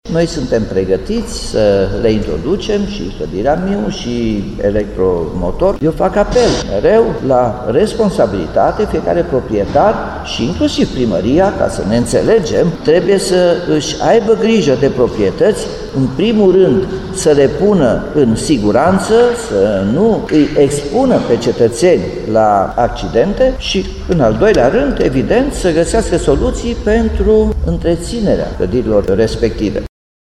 Clădirile publice pot fi reabilitate cu fonduri europene, însă aceste proiectele de finanţare a lucrărilor trebuie scrise, depuse şi aprobate pentru a obţine banii, spune primarul Nicolae Robu :